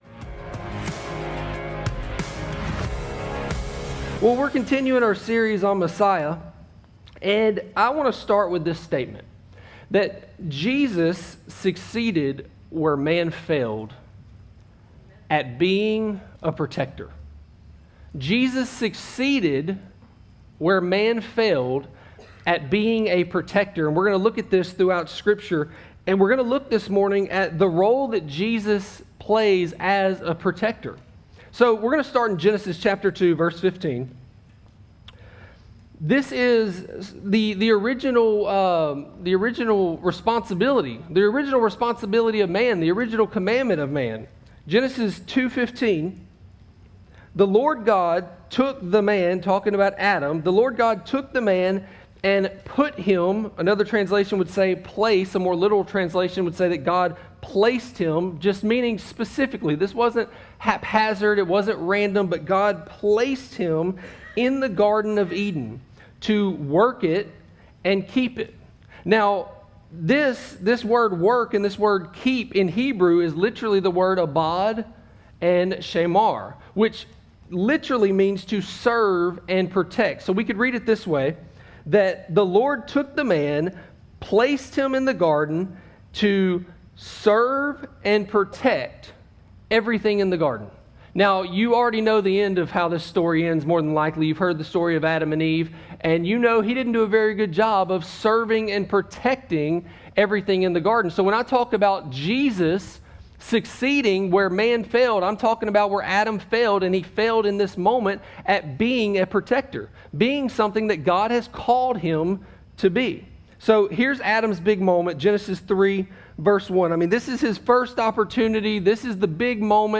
Sermons from One Life Church Alexandria